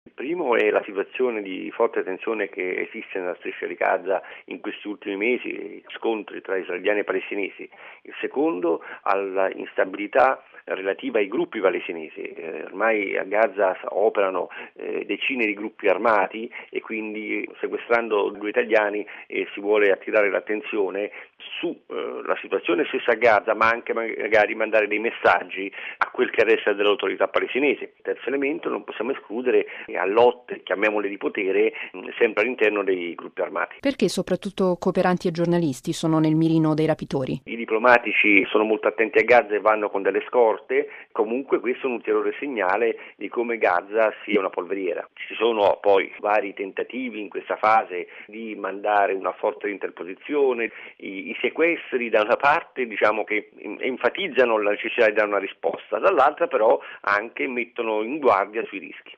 Rimane comunque l’allarme per i sequestri, dietro i quali ci sono diversi motivi, come spiega